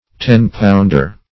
Search Result for " ten-pounder" : The Collaborative International Dictionary of English v.0.48: Ten-pounder \Ten"-pound`er\, n. (Zool.) A large oceanic fish ( Elops saurus ) found in the tropical parts of all the oceans.